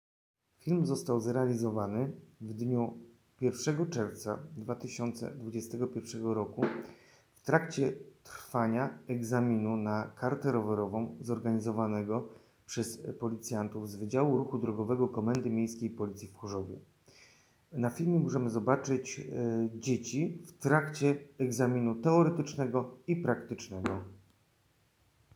Nagranie audio Adiodeskrypcja do filmu